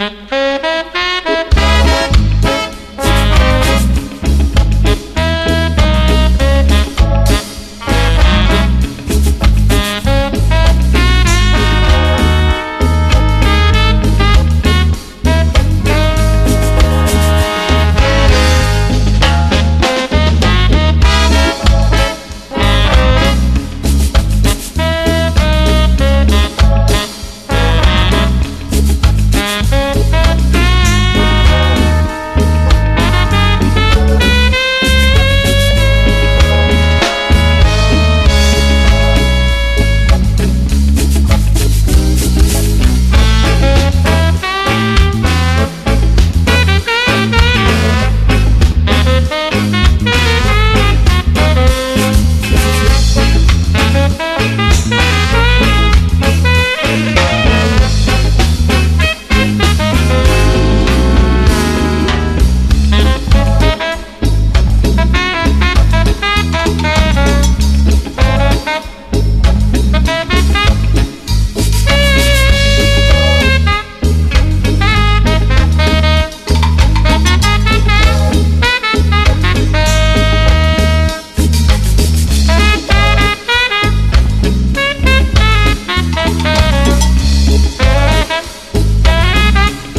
WORLD / REGGAE / SKA/ROCKSTEADY